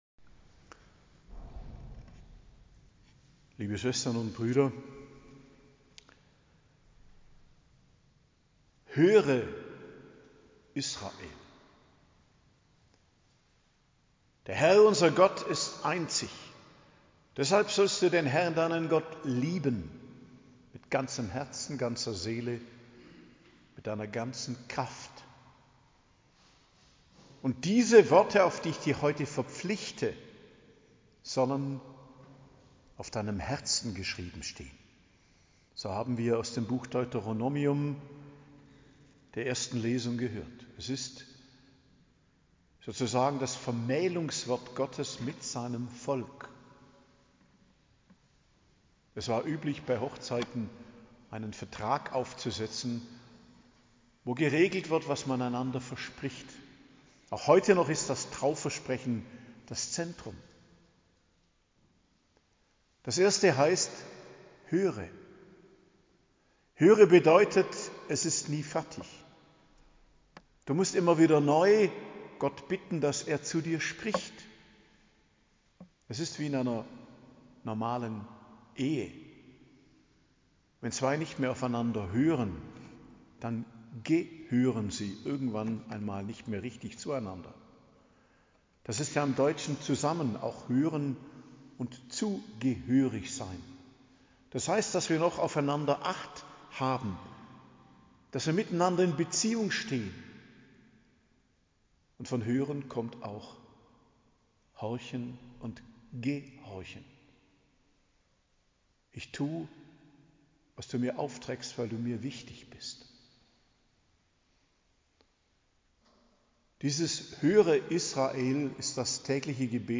Predigt zum 31. Sonntag im Jahreskreis, 3.11.2024 ~ Geistliches Zentrum Kloster Heiligkreuztal Podcast